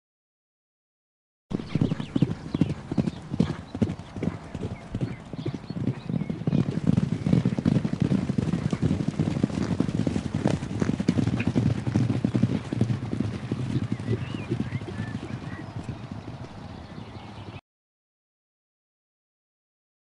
دانلود صدای دویدن اسب – یورتمه اسب 4 از ساعد نیوز با لینک مستقیم و کیفیت بالا
جلوه های صوتی